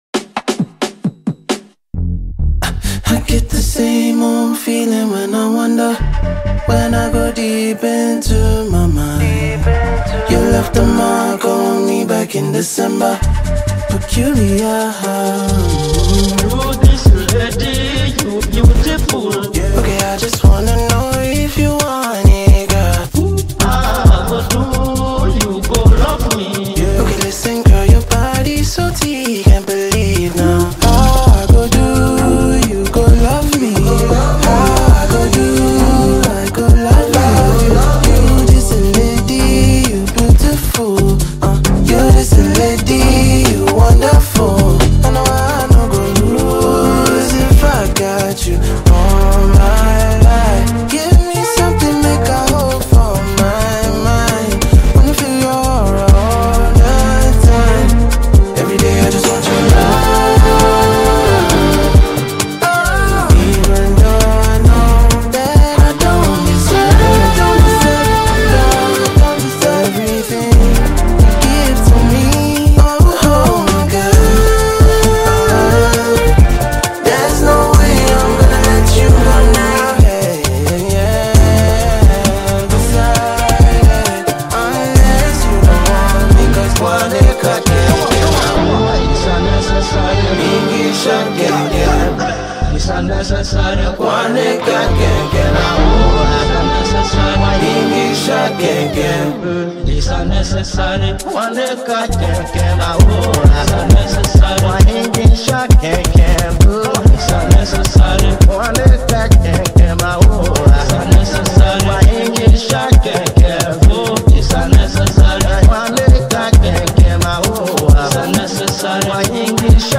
Nigerian singer-songsmith